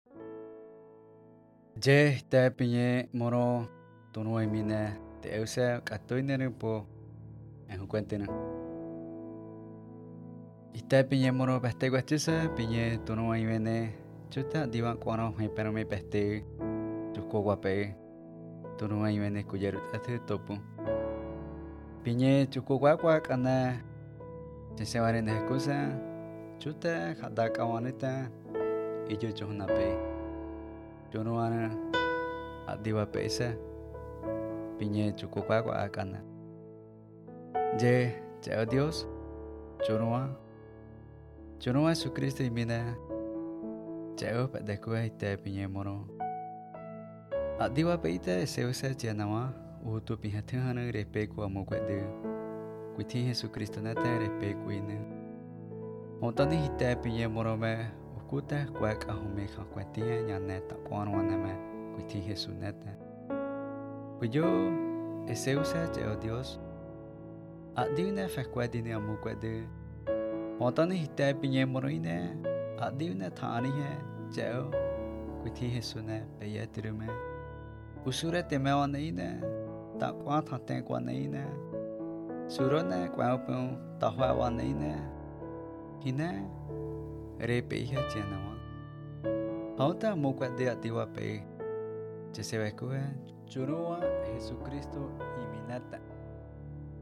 Today, I received a video call from the group of P “sound engineers” at their new studio.